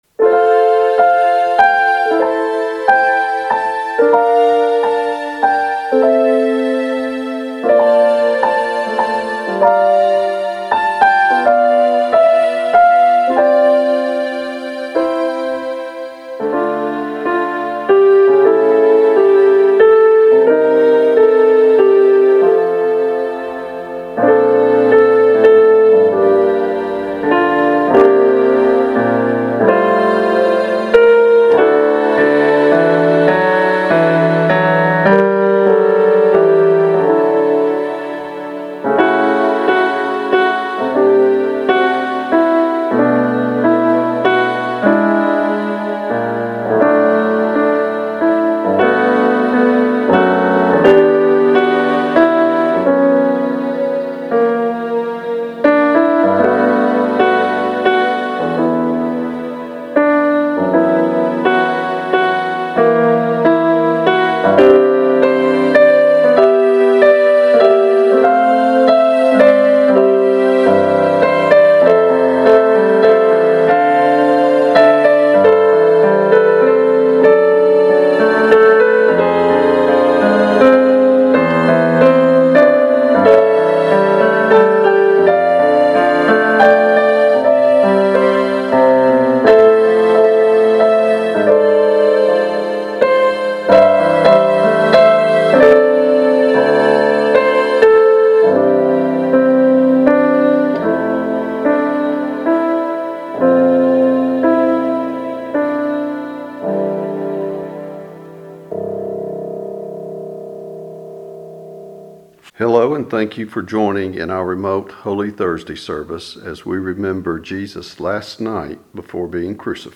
Prelude - 'When I Survey the Wondrous Cross"
Special Music - 'When I Survey the Wondrous Cross" by the Praise Team